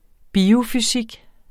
Udtale [ ˈbiːo- ]